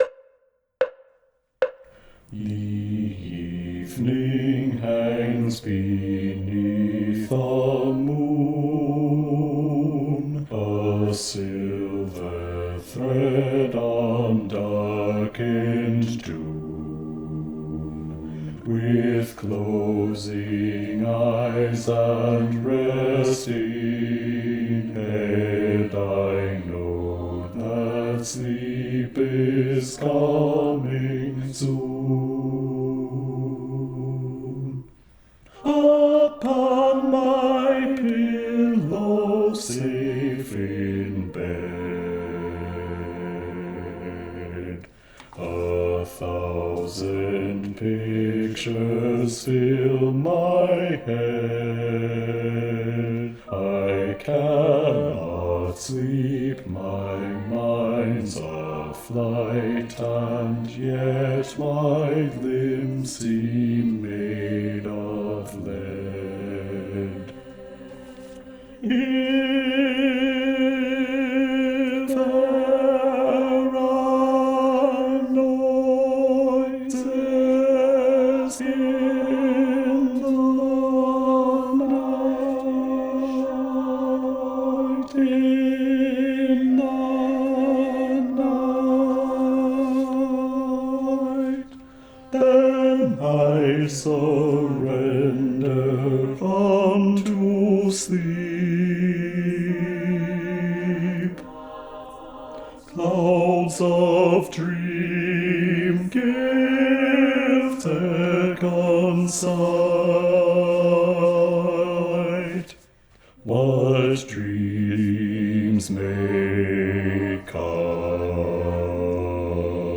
- Œuvre pour chœur à 8 voix mixtes (SSAATTBB)
Bass 2 Live Vocal Practice Track